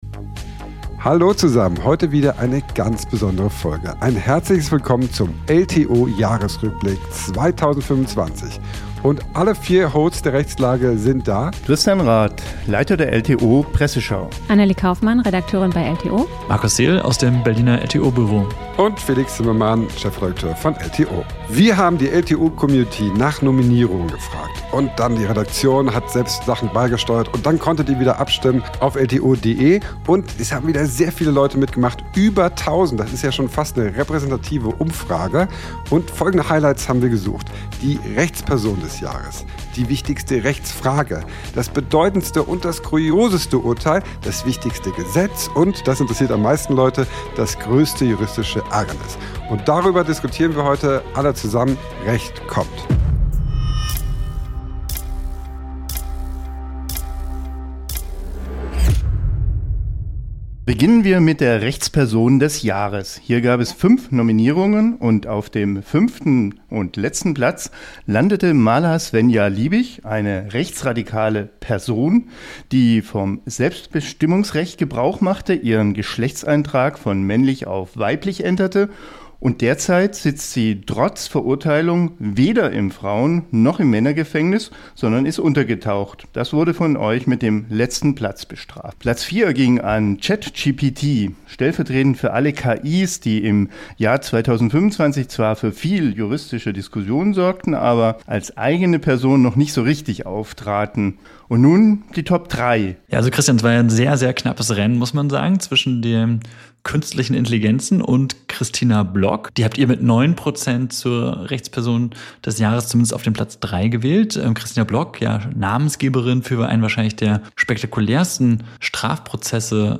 Über 1.200 LTO-Leserinnen und Leser hatten online über die Highlights abgestimmt, und zwar über Die Rechtsfrage des Jahres Die wichtige Person aus der Welt des Rechts Das bedeutendste Urteil des Jahres Das kurioseste Urteil des Jahres Das wichtigste Gesetz des Jahres Das größte juristische Ärgernis des Jahres Die Hosts diskutieren über die TOP3-Platzierungen der Leserwahl, ordnen ein, schauen zurück und nach vorne.